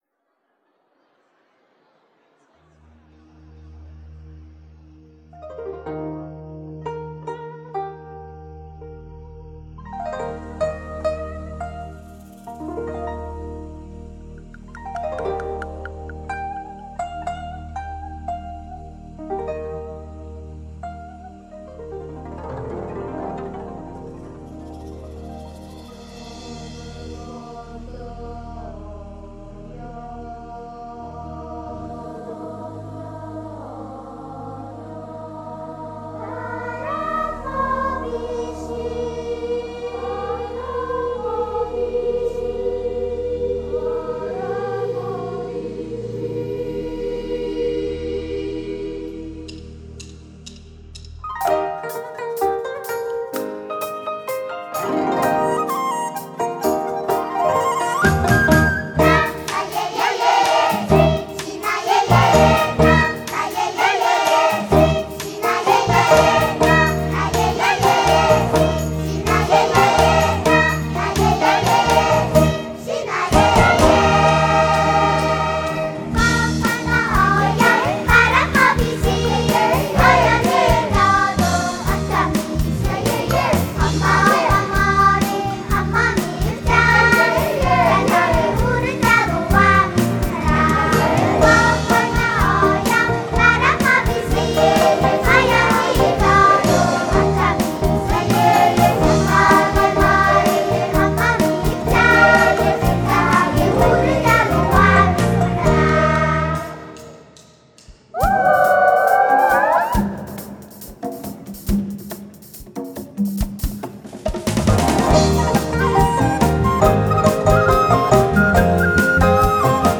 内蒙古儿童合唱团
（低音质）